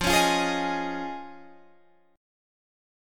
Fm7#5 chord